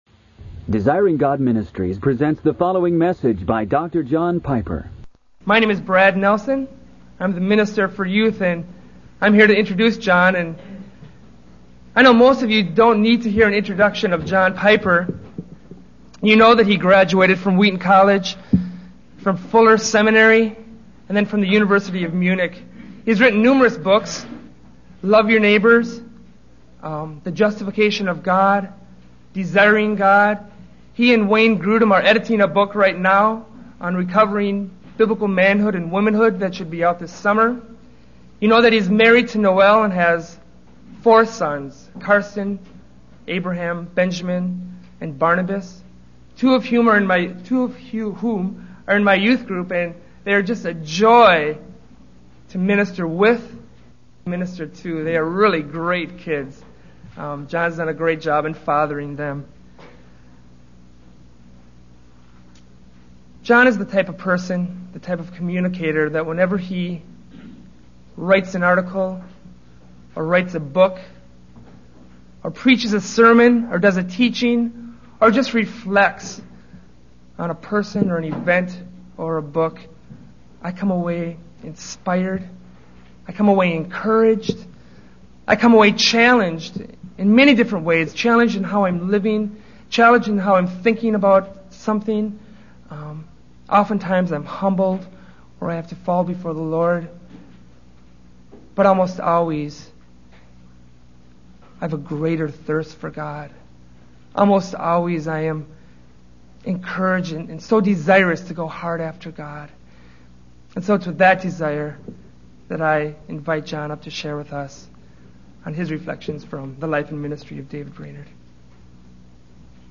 In this sermon, the speaker focuses on the life and ministry of David Brainerd, a missionary to the Native Americans in the 18th century. The speaker highlights the struggles that Brainerd faced, including physical ailments, loneliness, and opposition from the Indians.